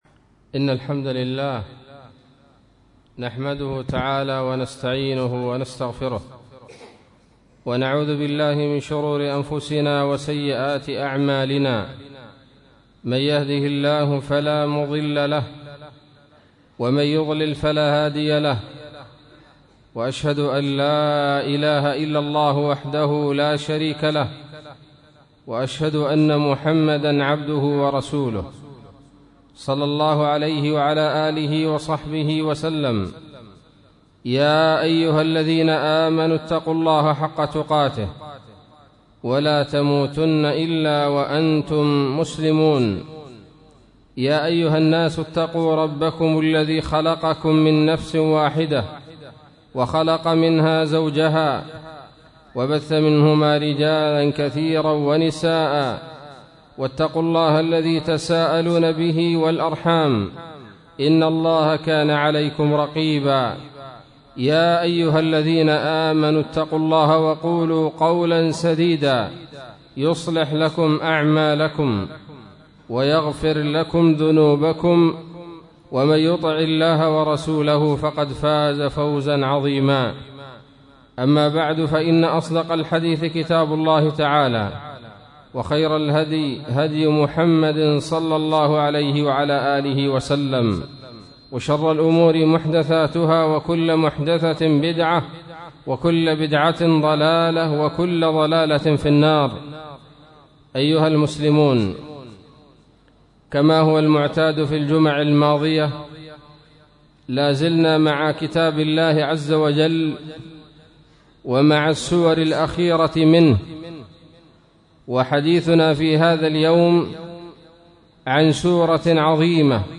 خطبة بعنوان